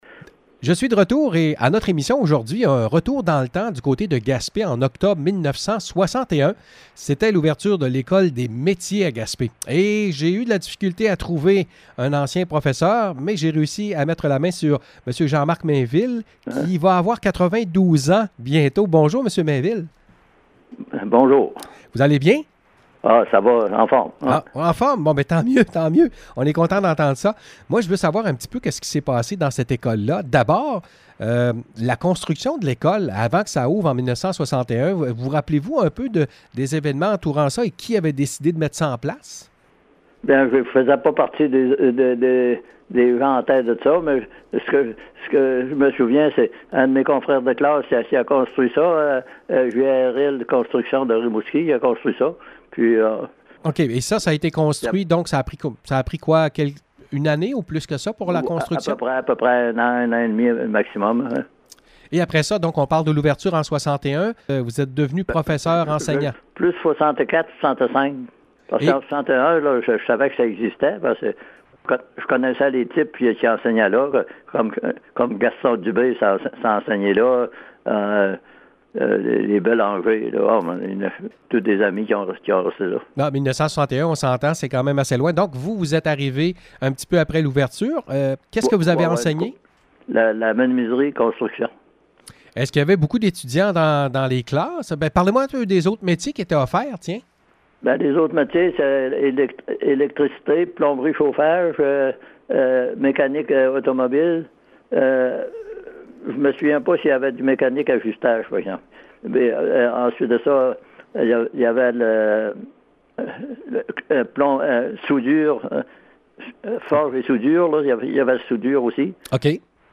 En 1961 c’était l’ouverture de l’école des métiers à Gaspé. Entretien avec un enseignant de l’époque